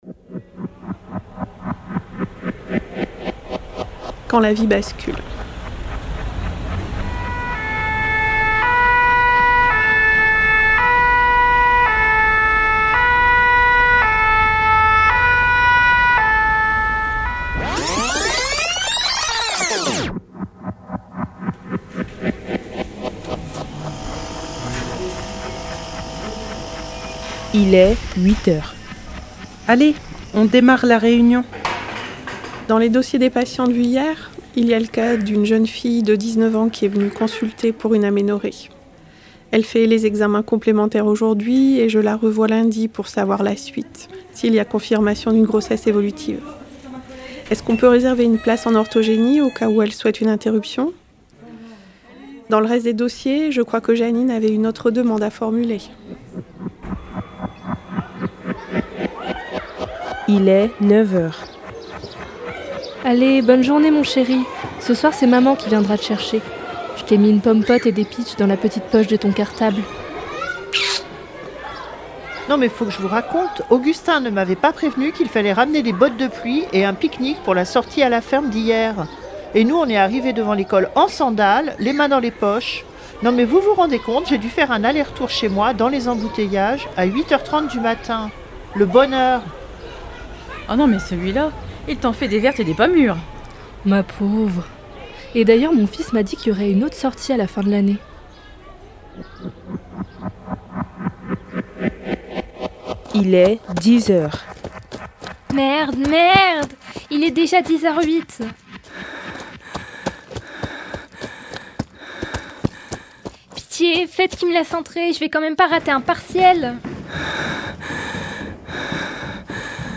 Création originale de l'atelier "fiction radiophonique" de Radio Primitive